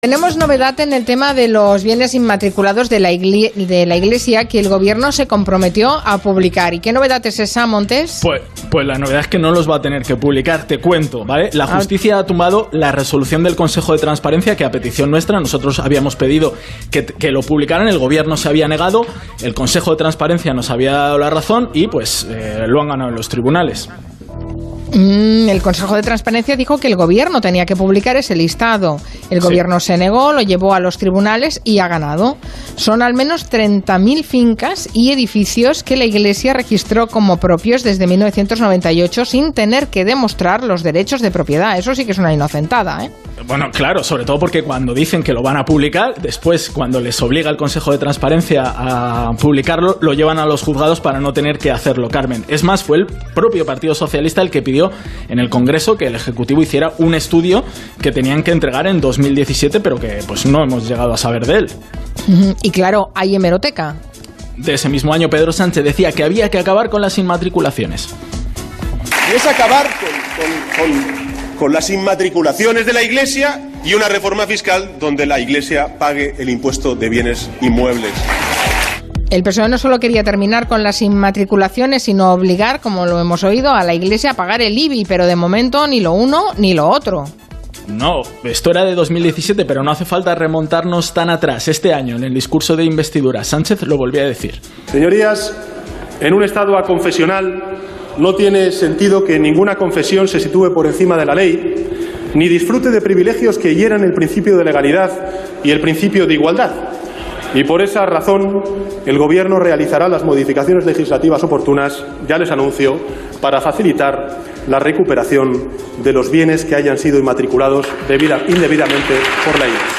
en Onda Cero